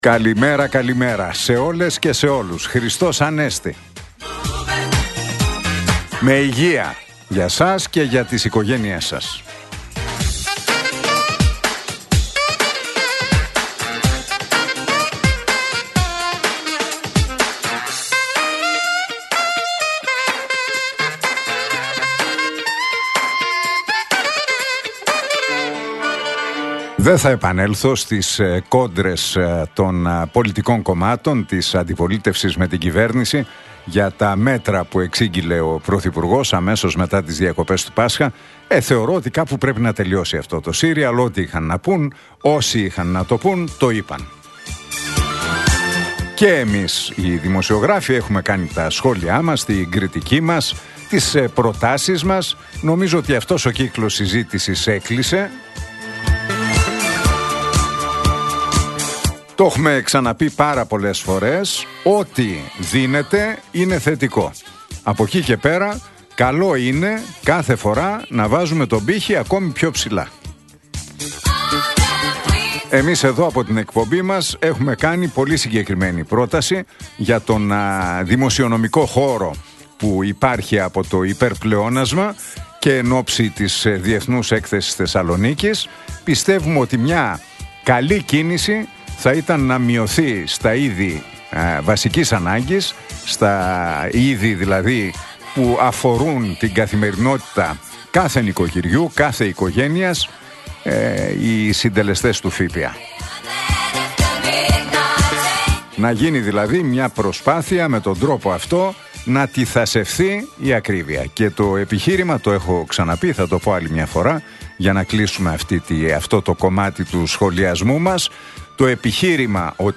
Ακούστε το σχόλιο του Νίκου Χατζηνικολάου στον ραδιοφωνικό σταθμό Realfm 97,8, την Πέμπτη 24 Απριλίου 2025.